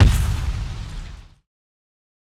SOUTHSIDE_fx_gun_impact.wav